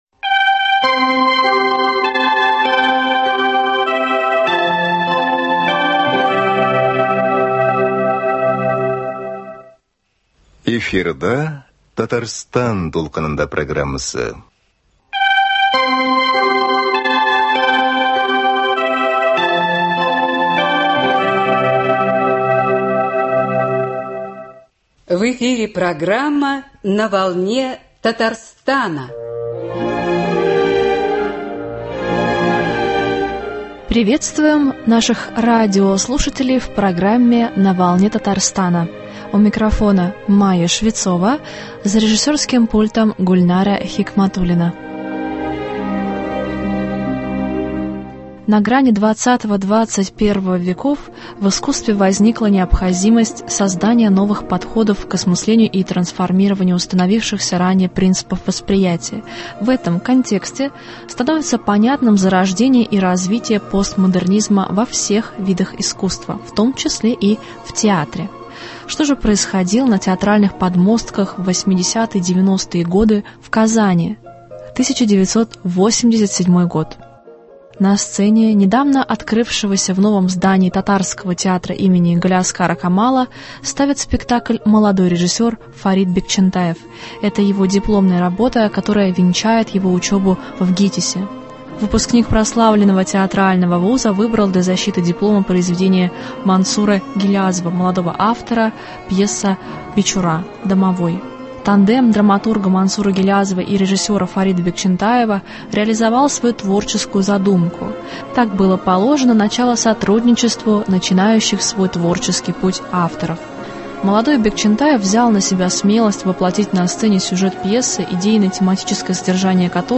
Гость студии — народный артист РФ и РТ Асгар Шакиров.